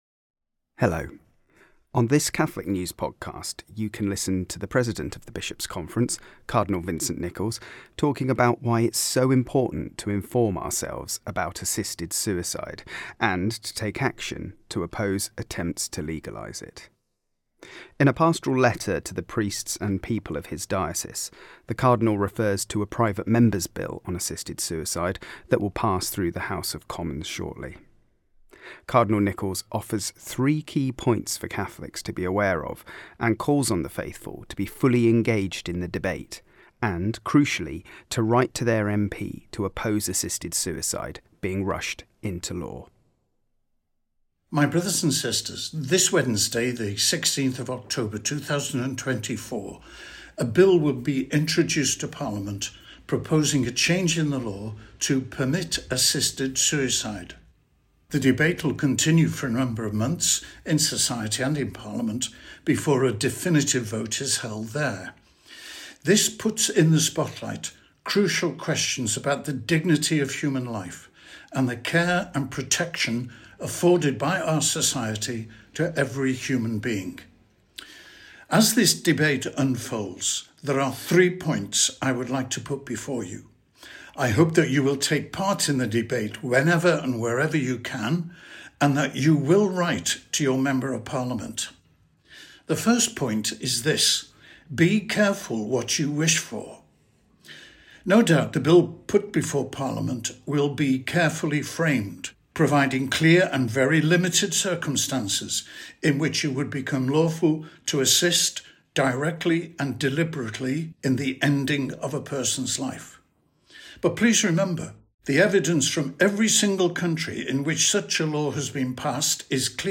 On this Catholic News podcast, you can listen to the President of the Bishops' Conference, Cardinal Vincent Nichols, talking about why it's so important to inform ourselves about assisted suicide and take action to oppose attempts to legalise it.